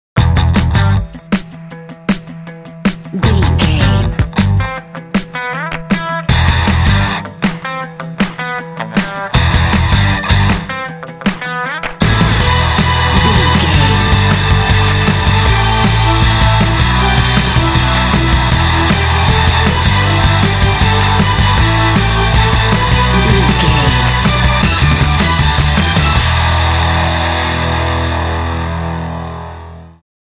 Aeolian/Minor
Fast
intense
powerful
energetic
bass guitar
electric guitar
drums
strings
heavy metal
symphonic rock